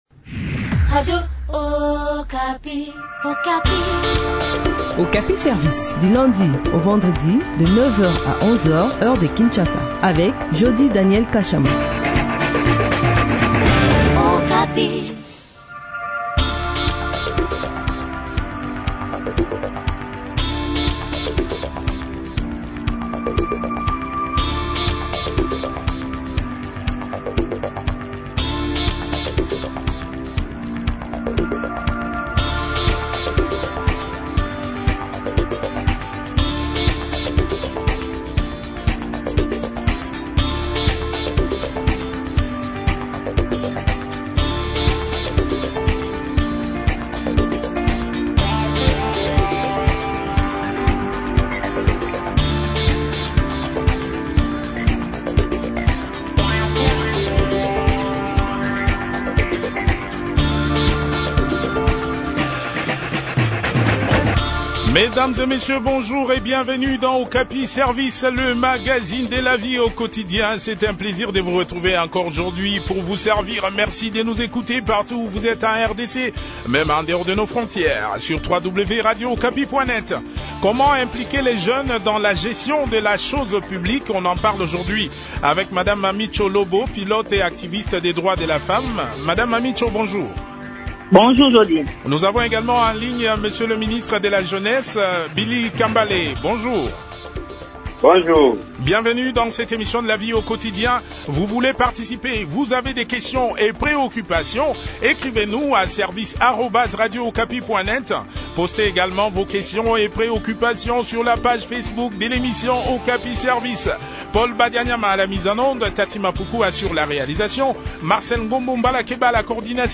Les éléments de réponse dans cet échange